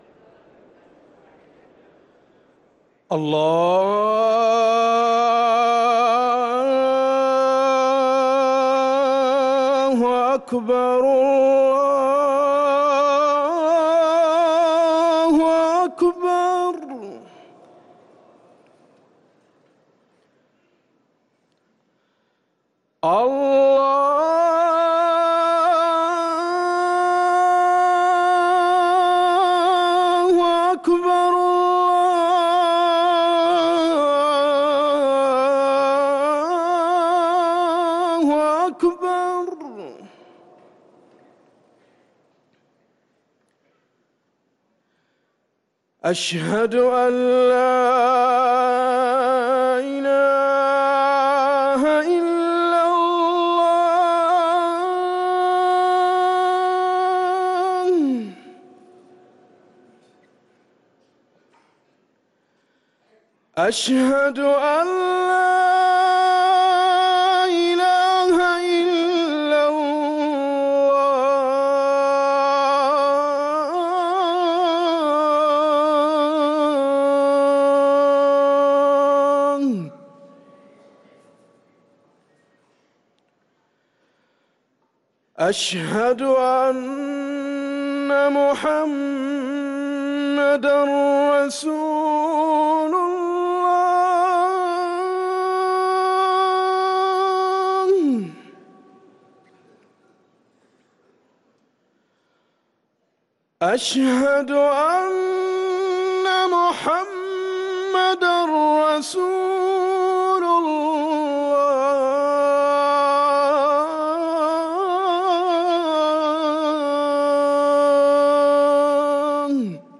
أذان العشاء
ركن الأذان